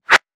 weapon_bullet_flyby_12.wav